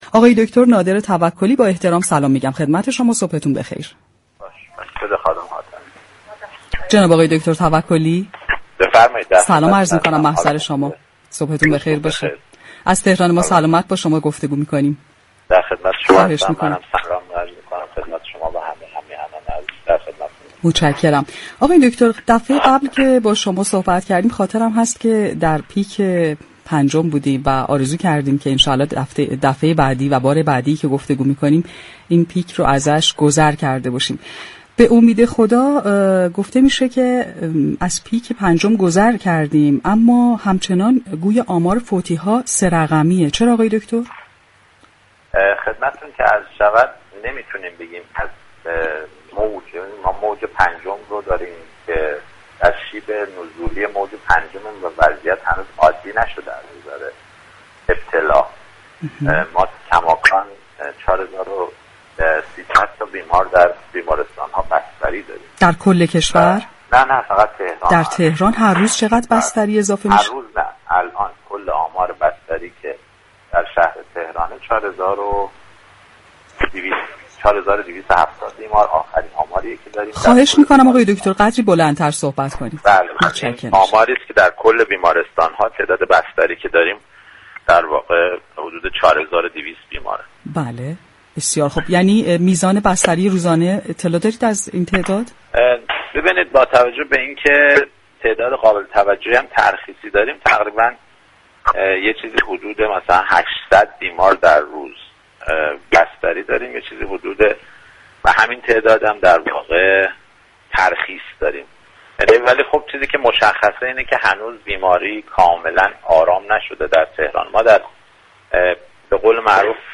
به گزارش پایگاه اطلاع رسانی رادیو تهران، نادر توكلی معاون درمان ستاد مبارزه با كرونای استان تهران در گفتگو با برنامه تهران ما سلامت رادیو تهران با تاكید بر اینكه هنوز در شیب نزولی موج پنجم كرونا قرار داریم گفت: وضعیت هنوز از نظر ابتلاء عادی نشده چون كماكان در شهر تهران حدود 4 هزار و 270 بیمار بستری كرونایی داریم.